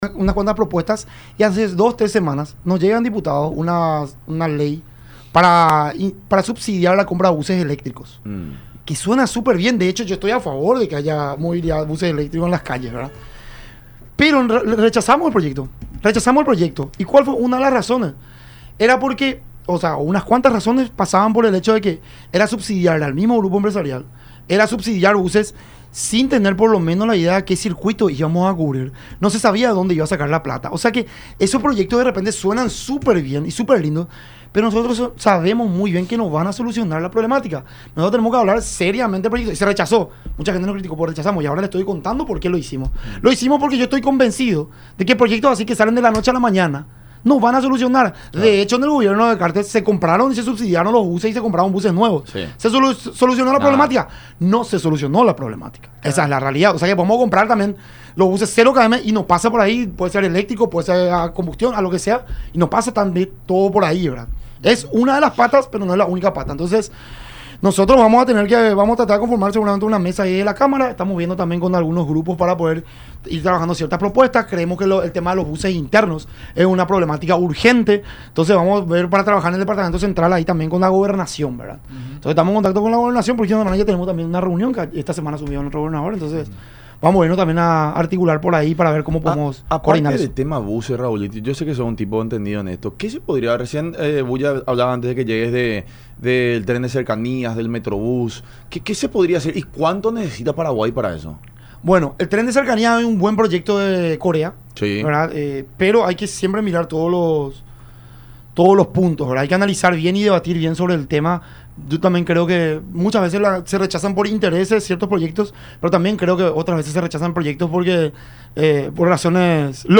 “Lo importante es que cada uno vote de acuerdo a sus convicciones y no a sus intereses. El punto crítico es donde está la prueba principal”, dijo el diputado Raúl Benítez en el programa “La Unión Hace La Fuerza” por Unión TV y radio La Unión.